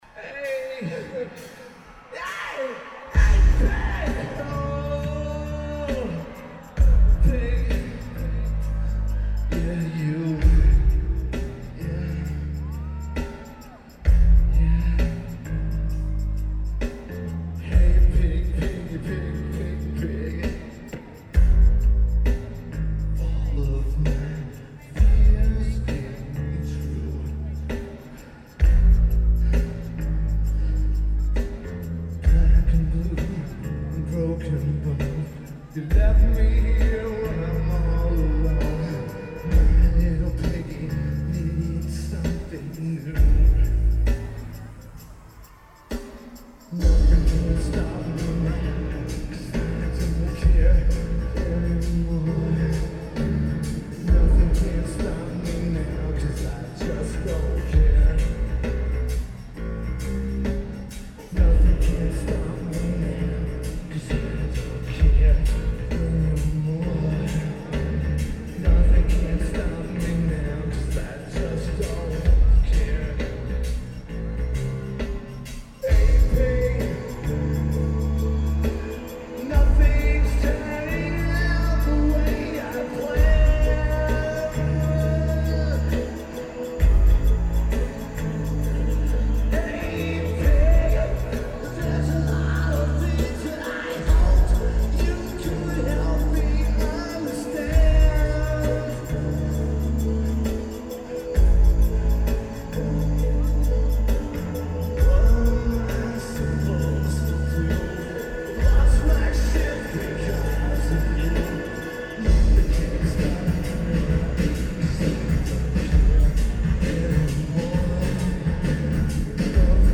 Buffalo Memorial Auditorium
Lineage: Audio - AUD (CSBs + Sony TCD-D7)